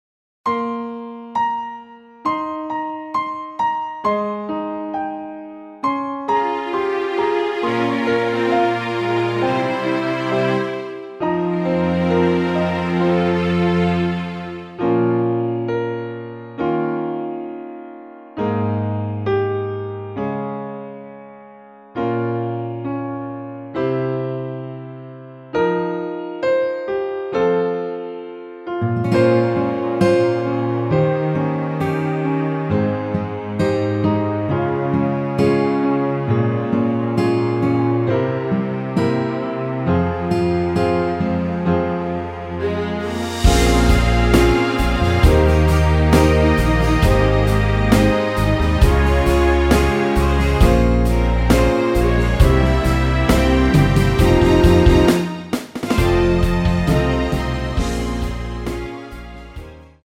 Fm
앞부분30초, 뒷부분30초씩 편집해서 올려 드리고 있습니다.